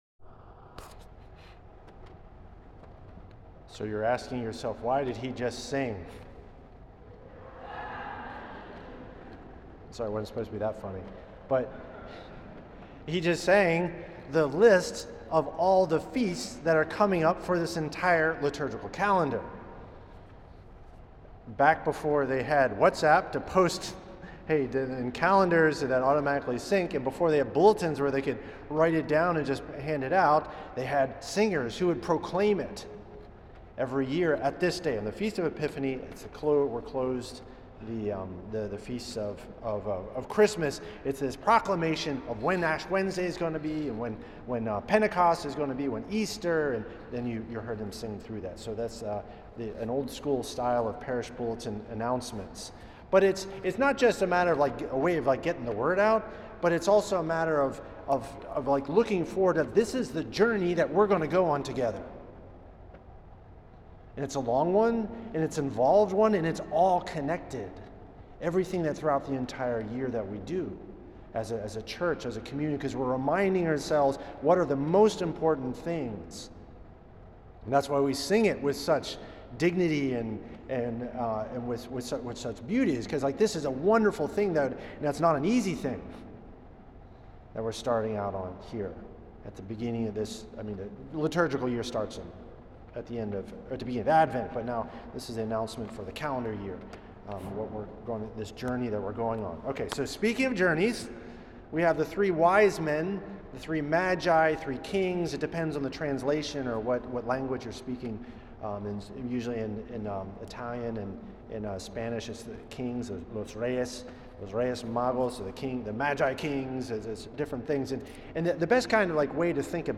Homily
at St. Patrick’s Old Cathedral in NYC